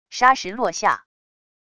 沙石落下wav音频